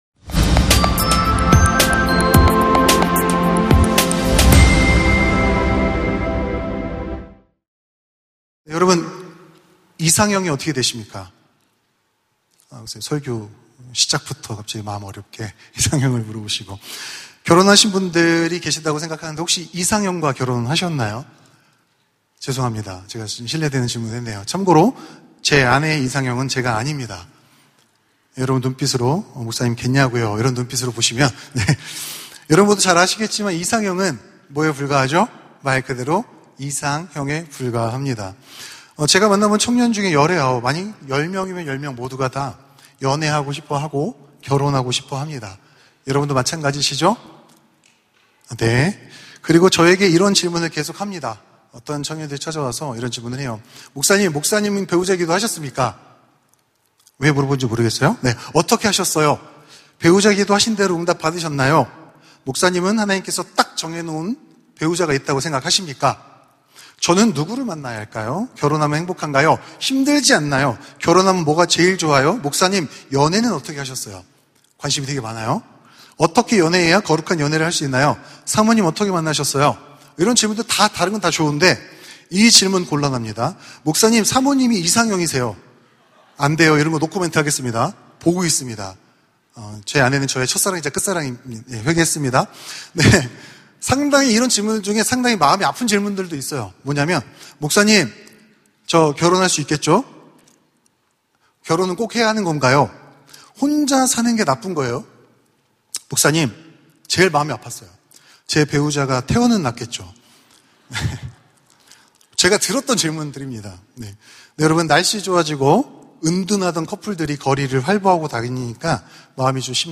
설교 : 파워웬즈데이 연애와 결혼, 나만 어렵나요?